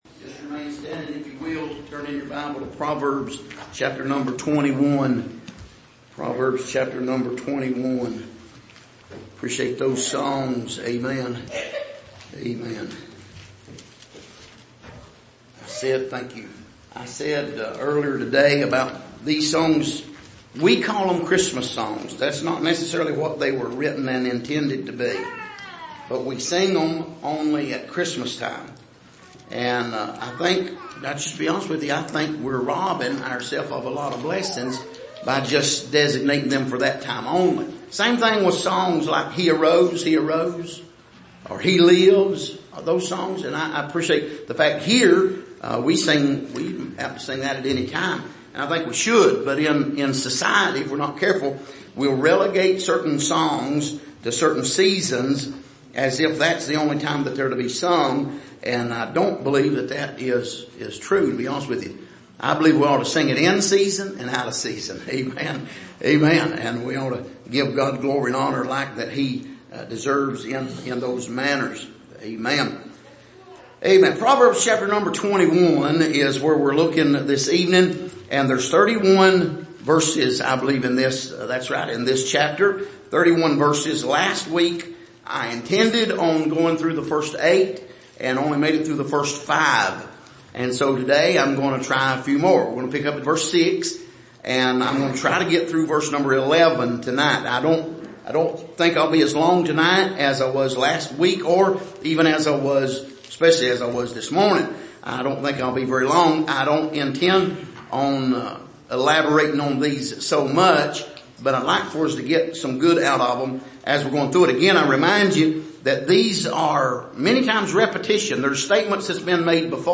Passage: Proverbs 21:6-11 Service: Sunday Evening